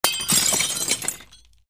Звуки разбитого стекла
Звук разбитого стекла при падении